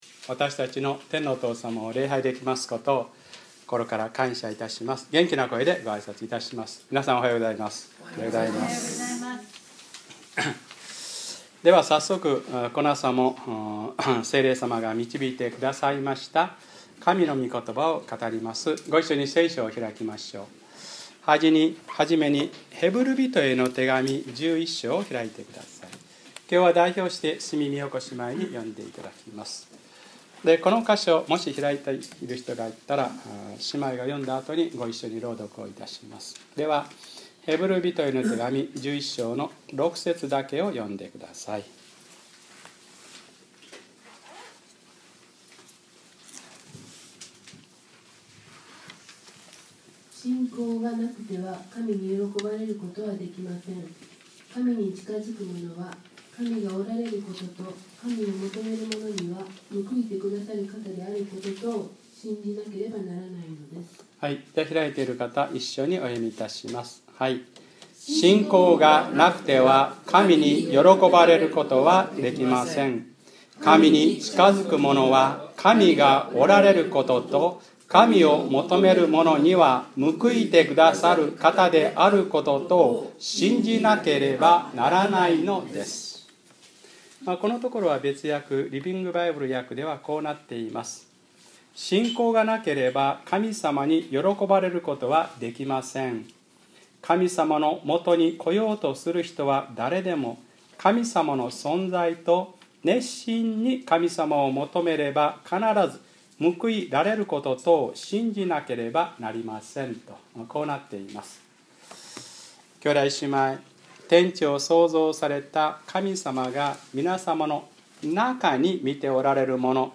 2012年7月1日(日）礼拝説教 『でもおことばどおり、網をおろしてみましょう』